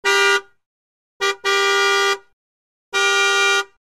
Звуки сигнала машины
На этой странице собраны разнообразные звуки автомобильных сигналов: от стандартных гудков до экстренных клаксонов.